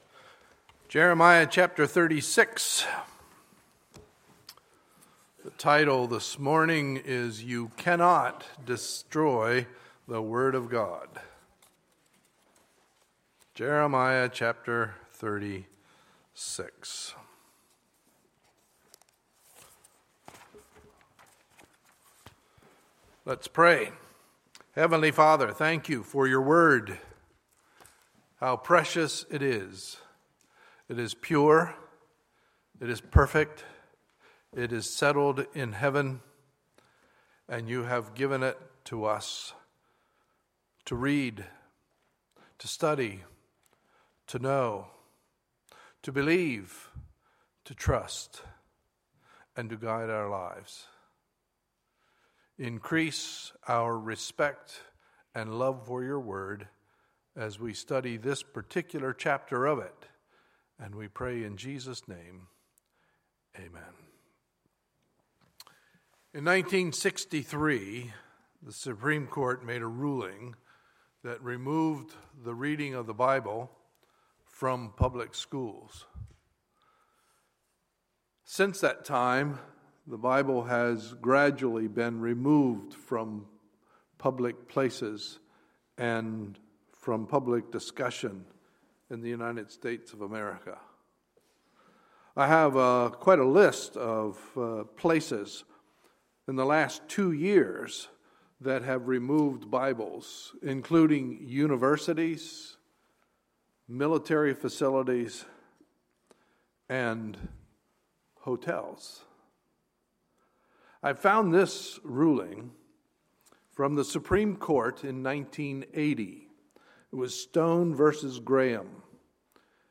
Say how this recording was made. Sunday, August 23, 2015 – Sunday Morning Service